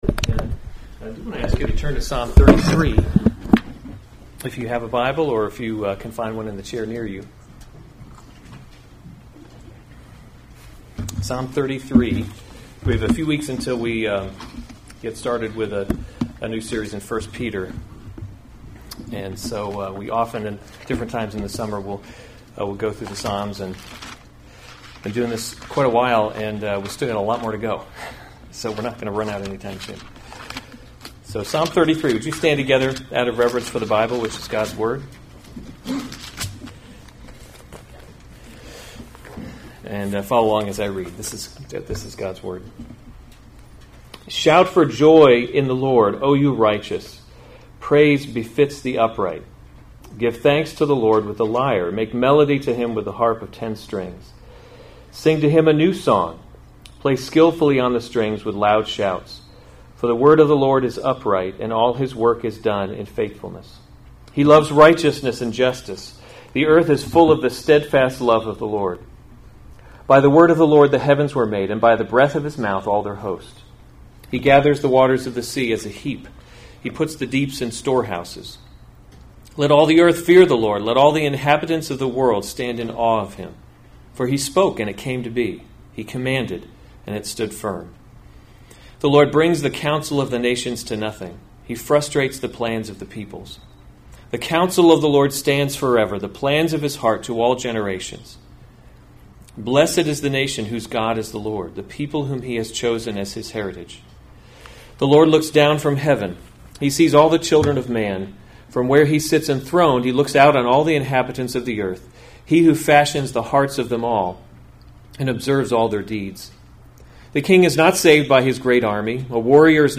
August 10, 2019 Psalms – Summer Series series Weekly Sunday Service Save/Download this sermon Psalm 33 Other sermons from Psalm The Steadfast Love of the Lord 33:1 Shout for joy in […]